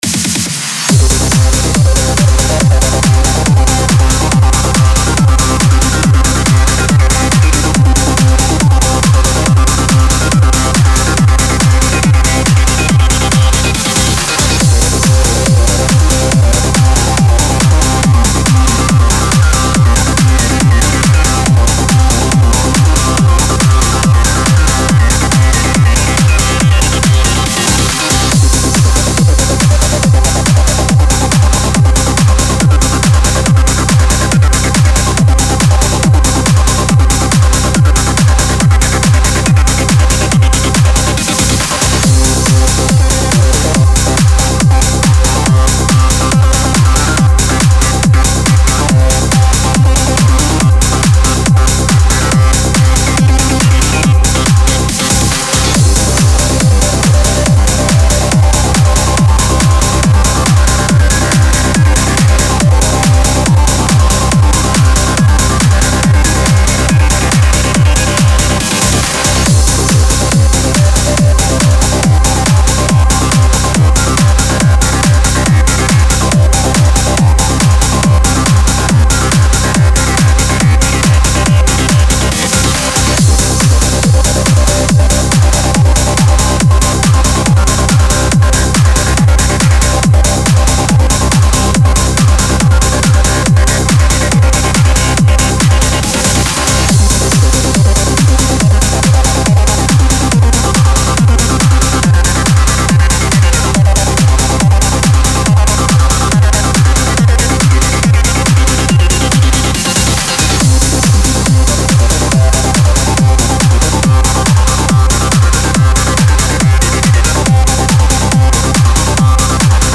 Type: Spire Midi
(Preview demo is 140 BPM)